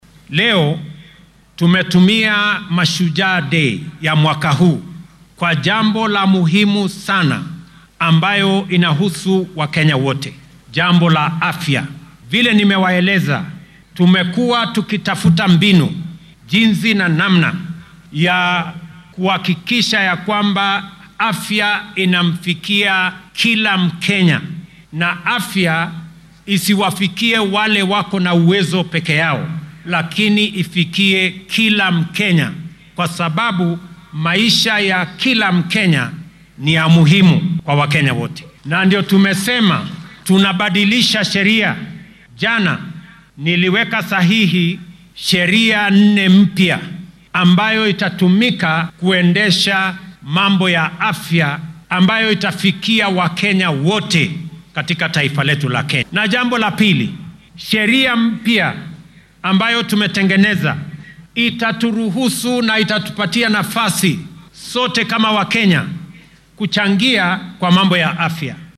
Madaxweynaha dalka William Ruto oo maanta khudbad ka jeedinayay munaasabadda xuska maalinta halyeeyada wadanka ee Mashujaa Day ayaa sheegay in dhammaan kenyaanka ay heli doonaan adeegyo caafimaad oo tayo leh, ay awoodaan sidoo kalena aan lagu kala takoorin.